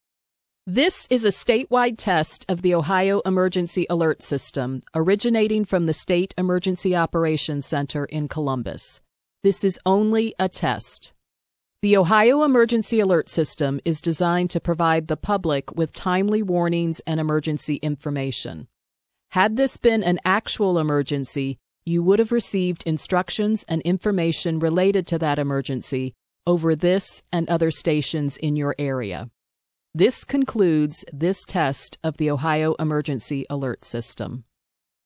EAS Broadcast Content audio/x-ipaws-audio-mp3 · 281285 bytes Embedded